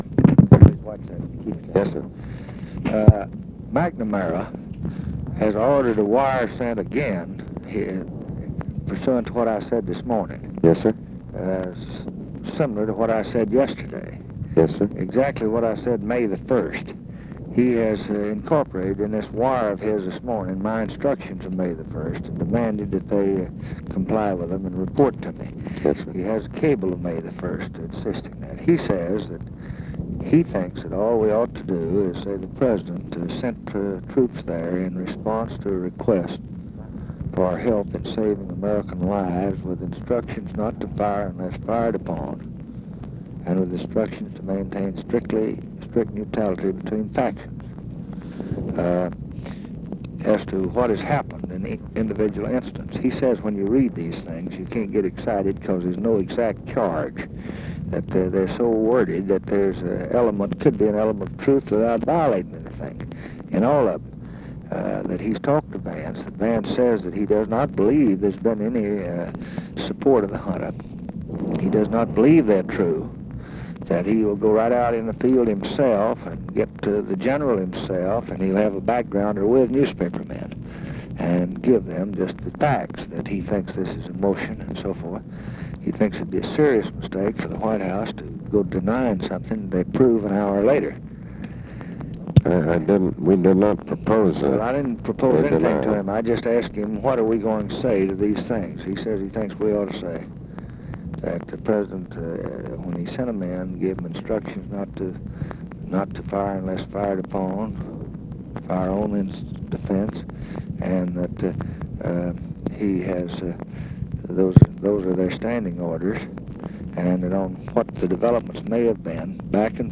Conversation with ABE FORTAS, May 20, 1965
Secret White House Tapes